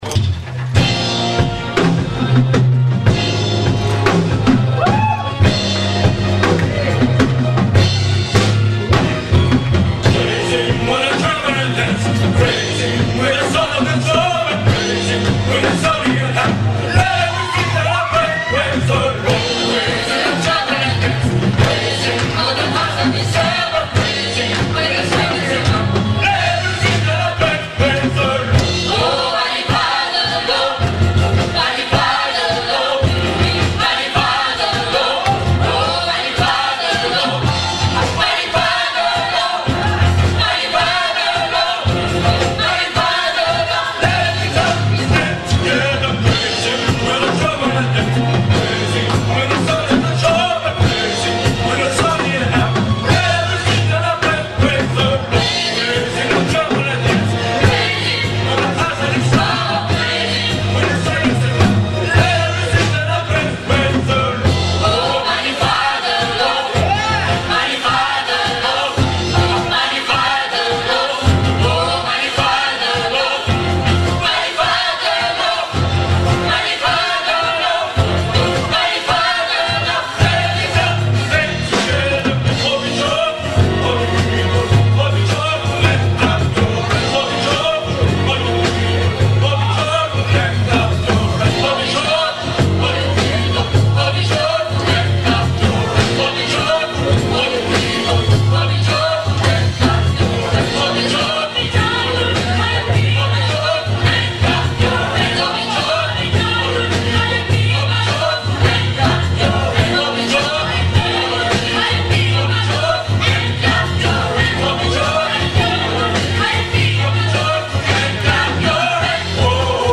Voici quelques extraits audio de la soirée récréative !